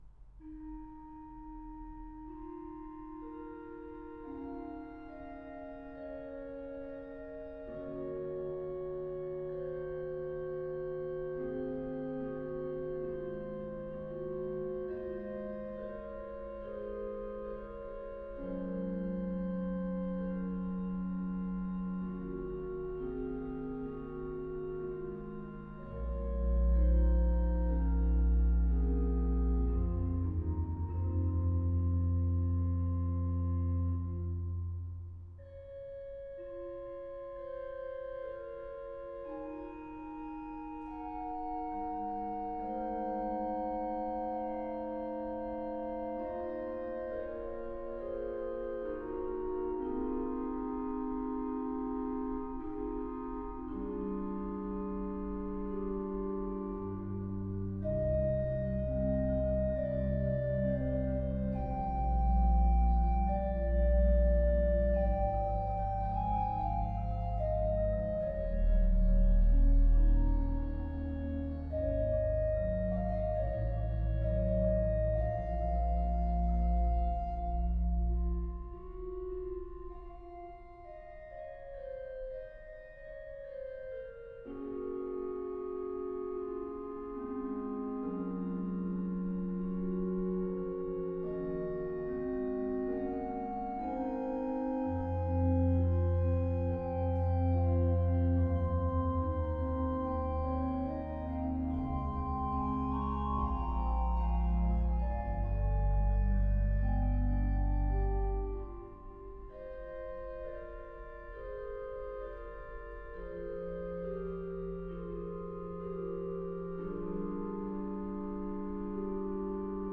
at Canongate Kirk in Edinburgh